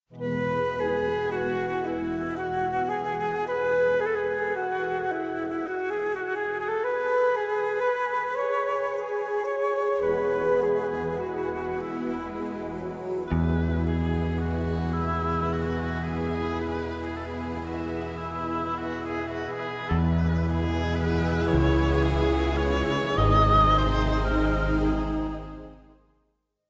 спокойные
без слов
классика
романтические
Знаменитая классическая музыка